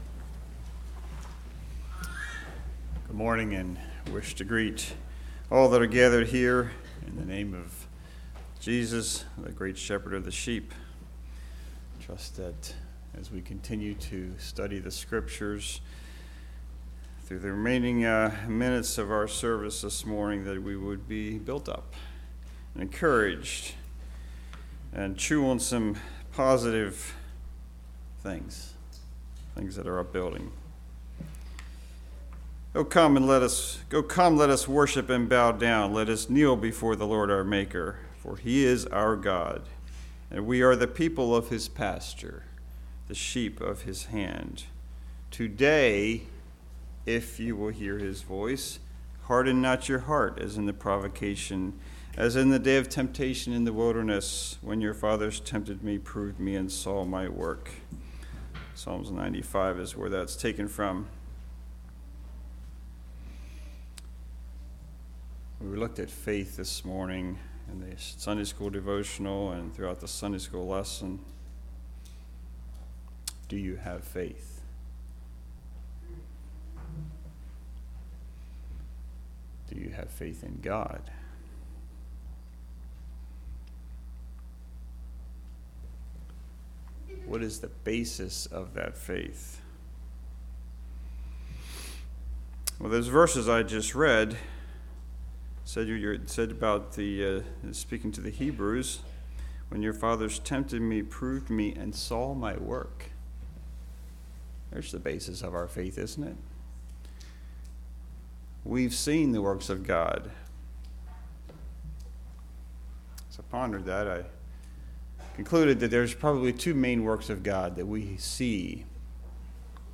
Congregation: Leola